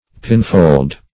Pinfold \Pin"fold`\, n. [For pindfold.